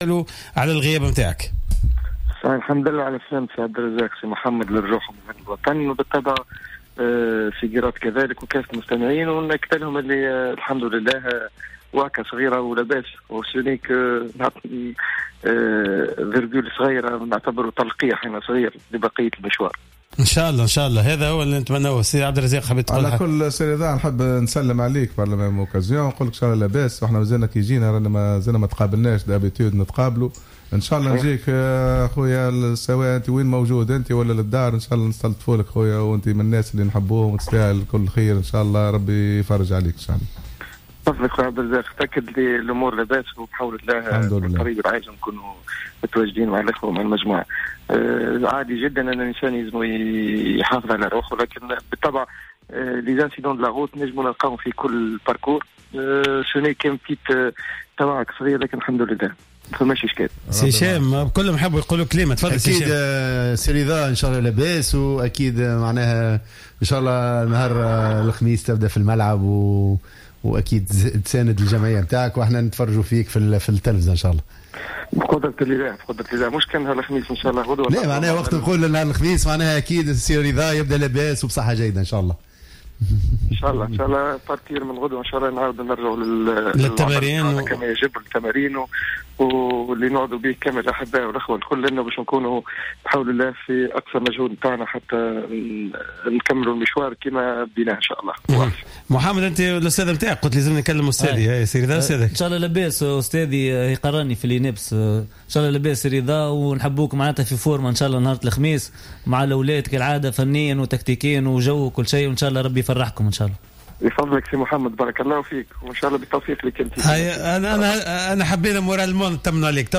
مداخلة في حصة "راديو سبور"